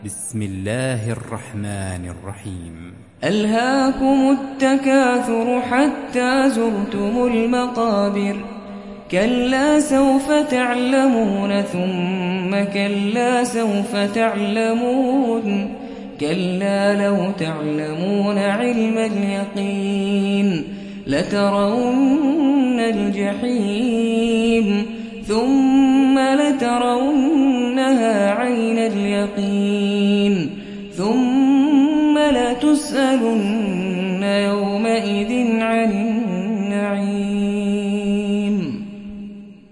Hafs থেকে Asim